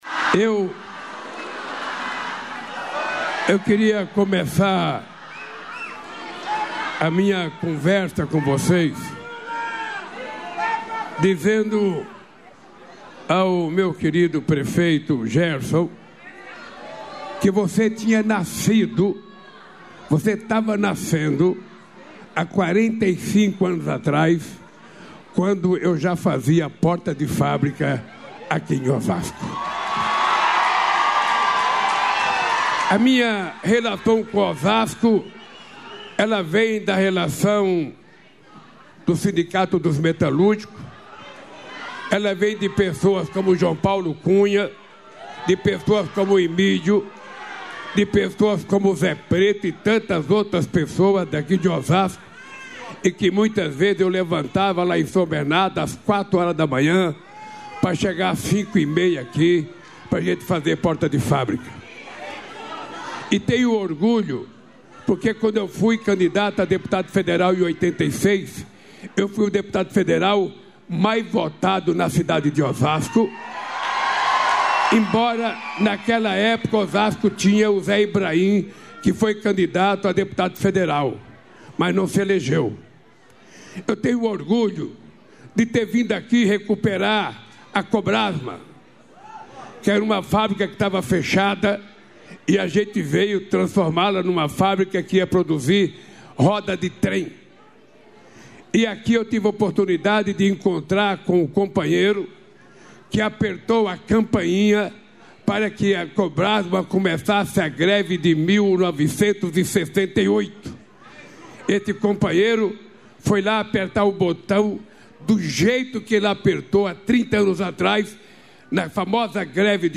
Integra do discurso do presidente Lula, nesta sexta-feira (25), no anúncio do Novo PAC Seleções 2025 Periferia Viva - Urbanização de favelas, em Osasco/SP.